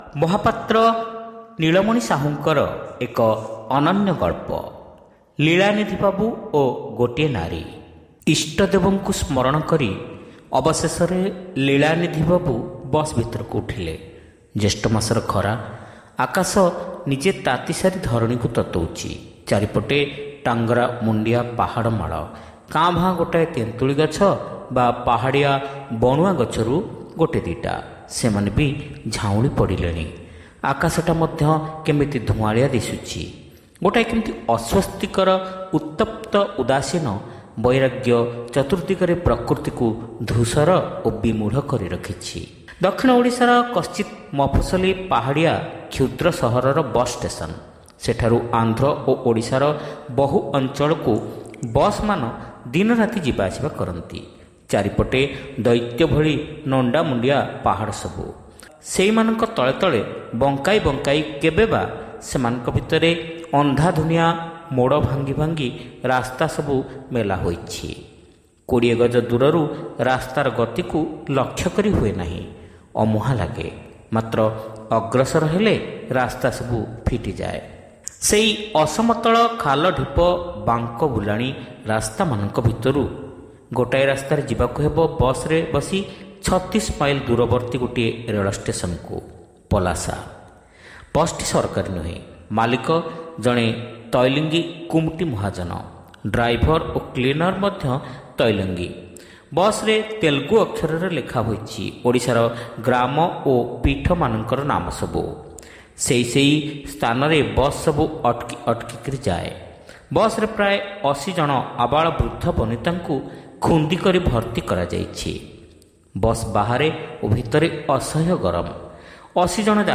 ଶ୍ରାବ୍ୟ ଗଳ୍ପ : ଲୀଳାନିଧି ବାବୁ ଓ ଗୋଟିଏ ନାରୀ (ପ୍ରଥମ ଭାଗ)